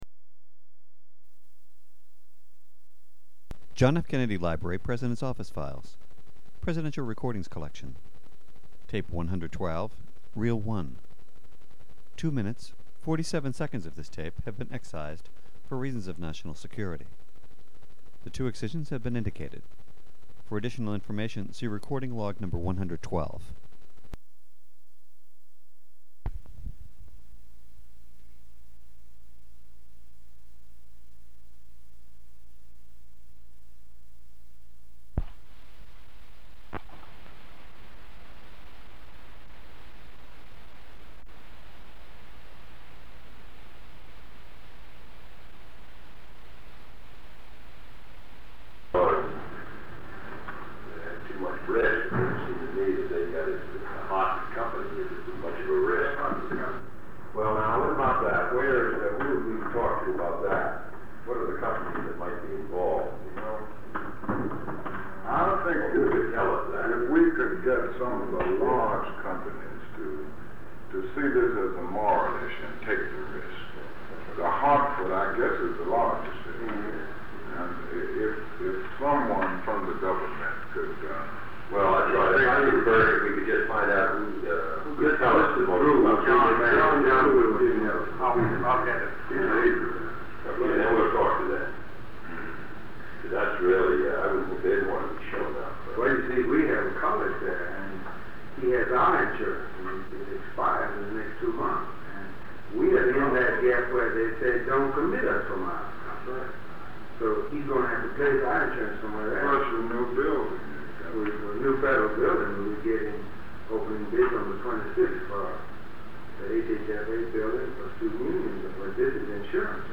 Secret White House Tapes | John F. Kennedy Presidency Meetings: Tape 112.